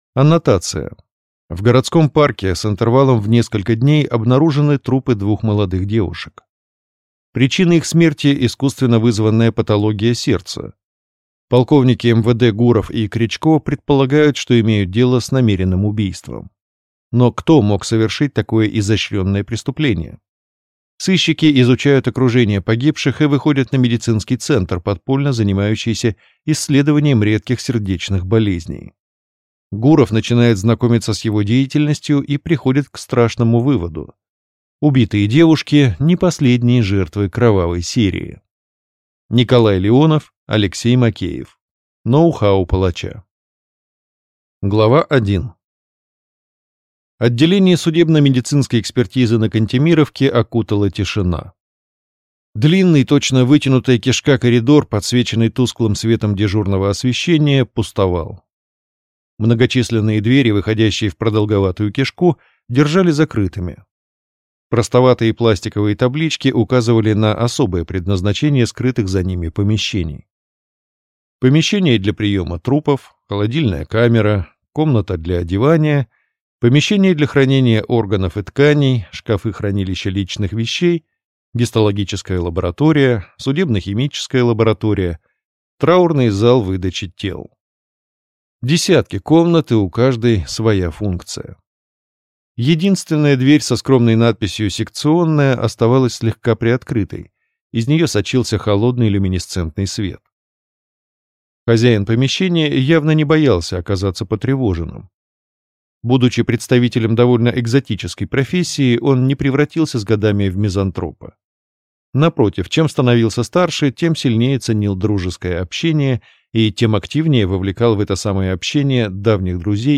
Аудиокнига Ноу-хау палача (сборник) | Библиотека аудиокниг